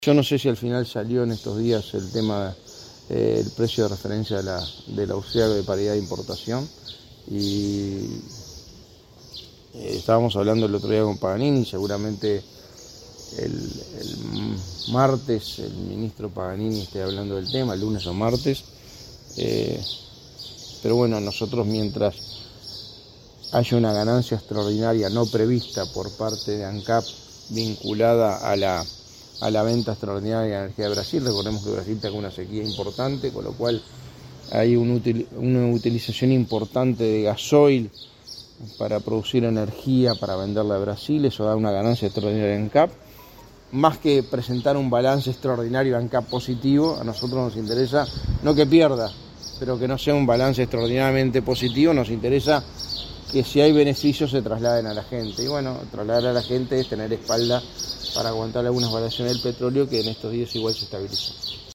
indicó este domingo en rueda de prensa.